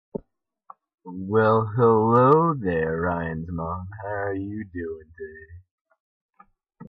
Tags: Freedom. DEEP. Nice